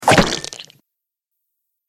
دانلود آهنگ دعوا 44 از افکت صوتی انسان و موجودات زنده
جلوه های صوتی
دانلود صدای دعوا 44 از ساعد نیوز با لینک مستقیم و کیفیت بالا